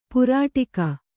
♪ purāṭika